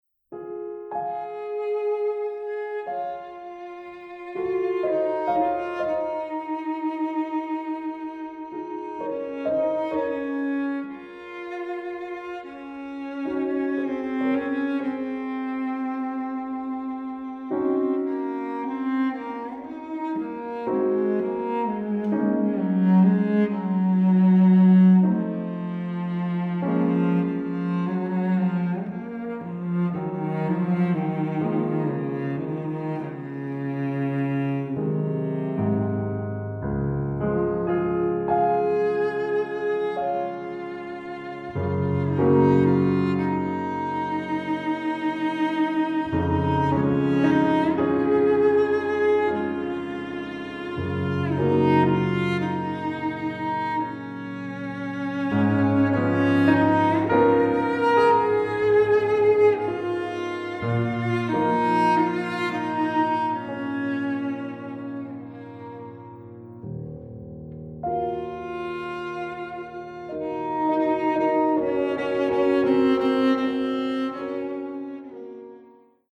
Piano
Classical